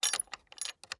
Doors Gates and Chests
Lock Unlock.wav